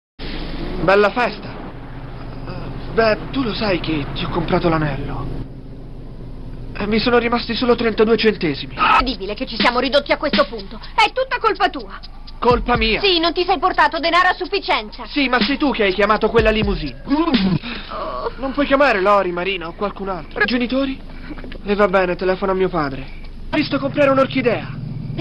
nel film TV "La sera del ballo", in cui doppia Matthew Perry.